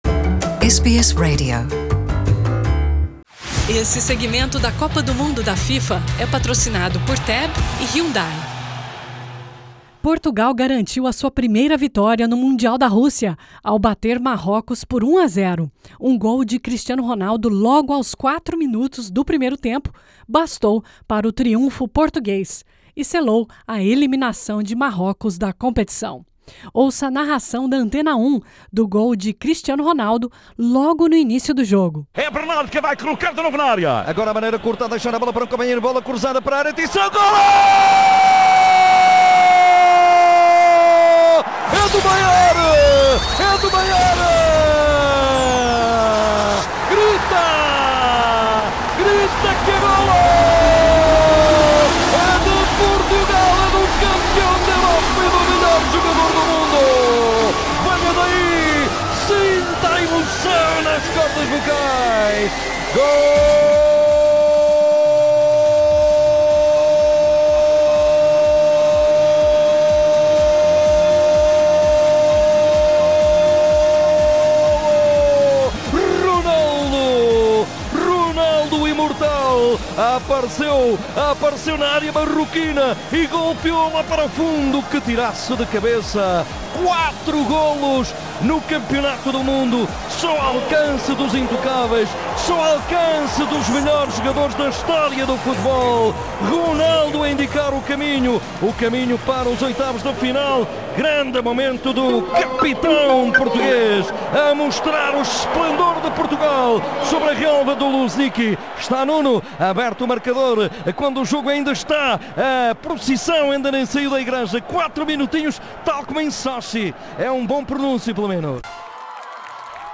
Ouça aqui a narração emocionada do gol que surpreendeu o Marrocos logo aos quatro minutos do primeiro tempo.
A SBS em Português transmite todos os jogos de Portugal e Brasil com narração da Antena 1 e Globo/CBN.